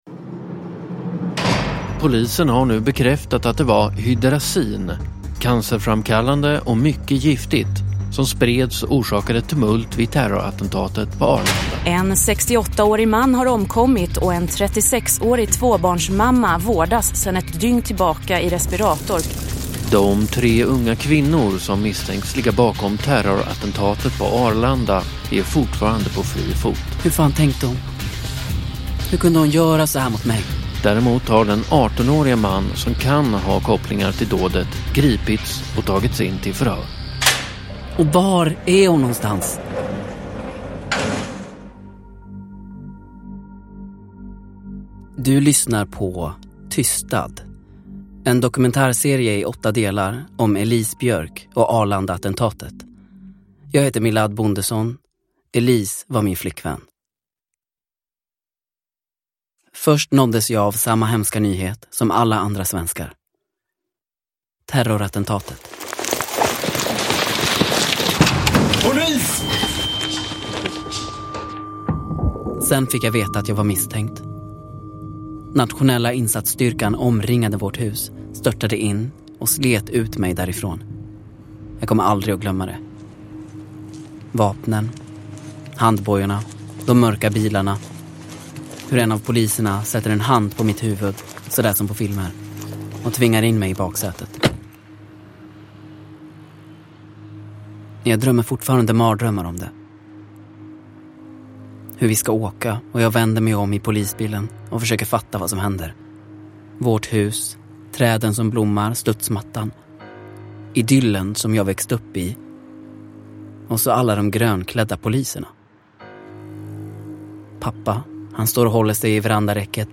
TYSTAD är en fiktiv dokumentärserie av Åsa Anderberg Strollo och Lisa Bjärbo.